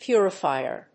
音節pú・ri・fì・er 発音記号・読み方
/ˈpjʊrʌˌfaɪɝ(米国英語), ˈpjʊrʌˌfaɪɜ:(英国英語)/